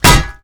anvil_land.ogg